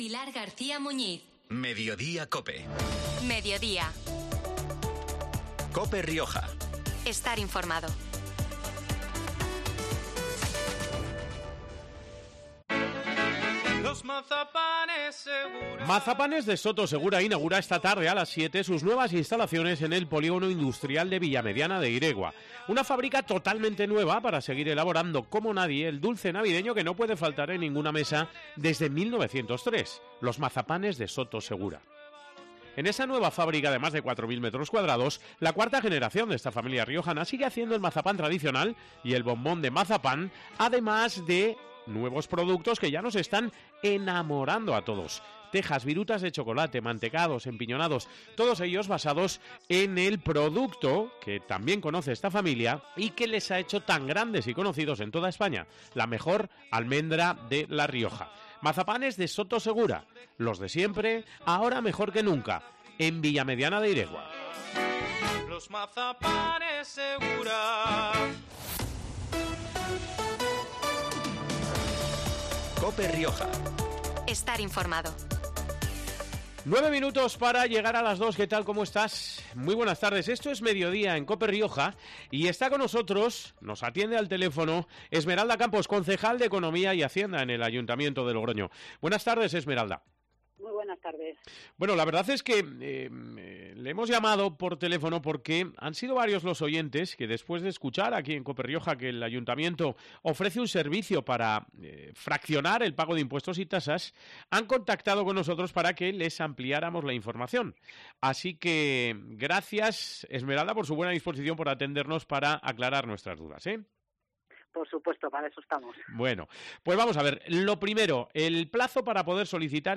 La concejala de Economía y Hacienda, Esmeralda Campos, detalla cuándo, cómo y quiénes pueden beneficiarse del plan especial de pagos del Ayuntamiento...